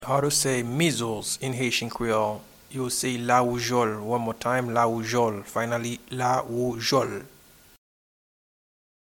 Pronunciation and Transcript: